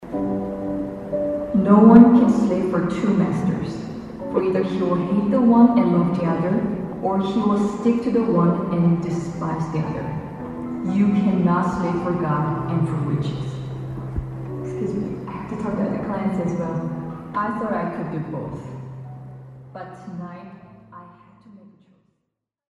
Det mest påfallende er hvor stille det er mulig for en så stor forsamling å være.
I den store messehallen på Lillestrøm blir foredragene delt på storskjerm.